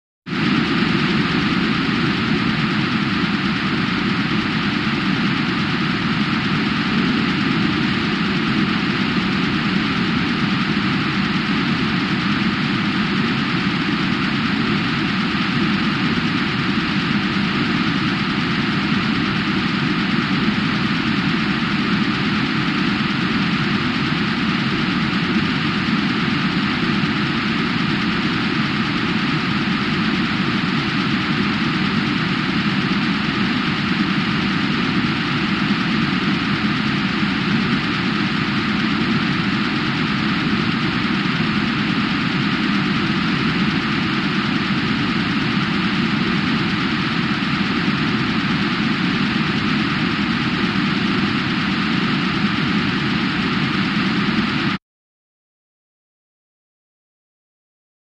Synth Spaceship Interior 2; Higher Pitched And With More Airy Texture Than FX 96.